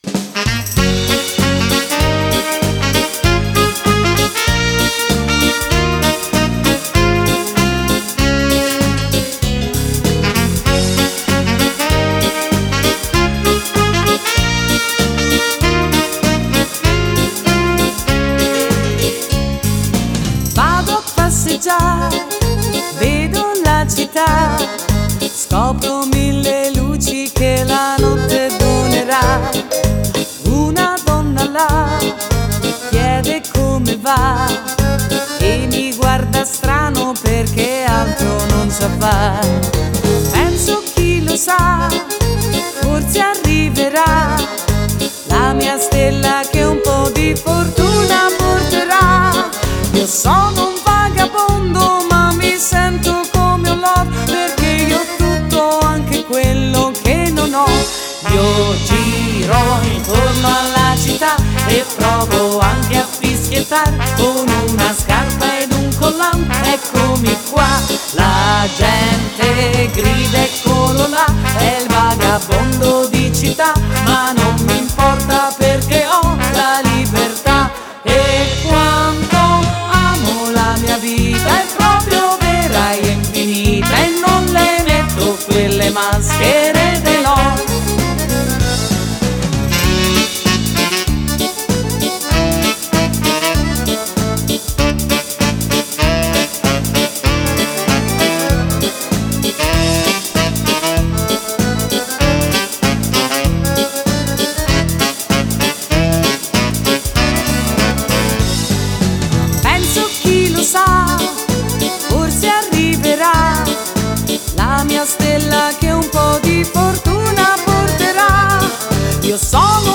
(versione donna) Fox
(Fox cantato)